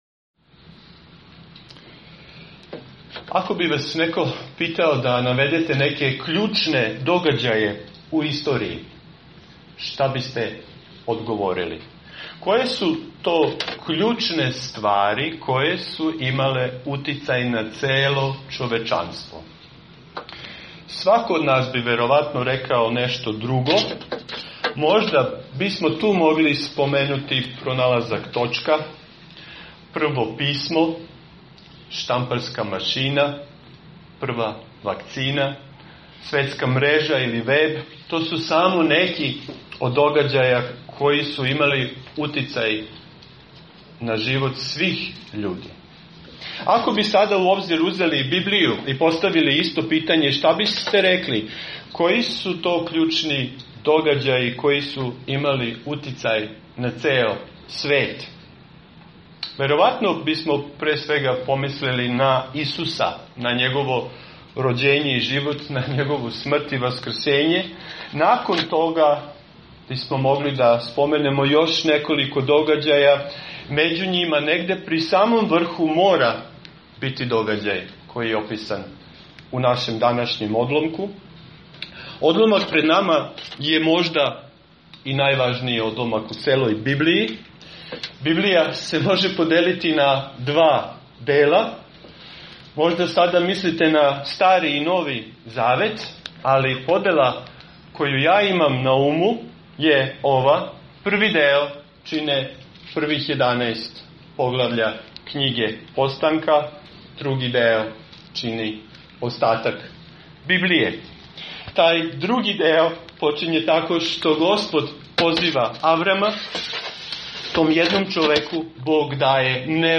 Serija: Avram: otac svih koji veruju | Poslušajte propoved sa našeg bogosluženja.